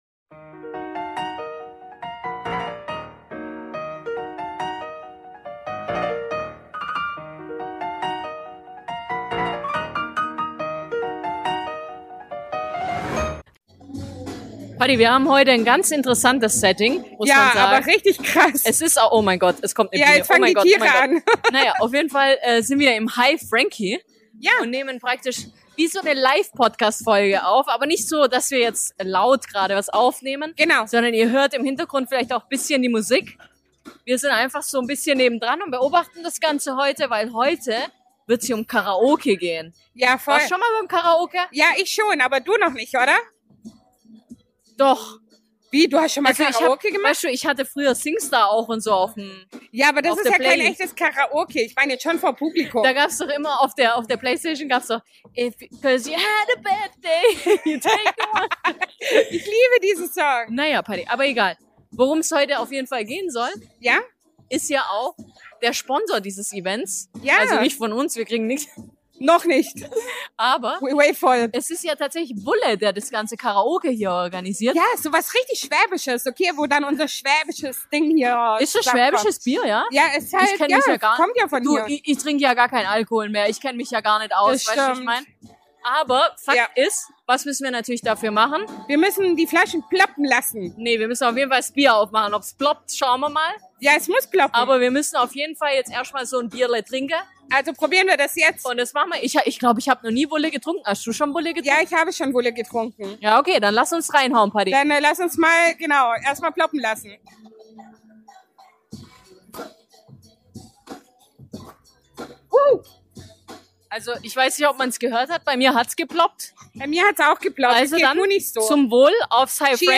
Ps. Der Sound ist etwas anders wie sonst, aber er ist real, so wie das Event und der Vibe den wir das gefühlt haben.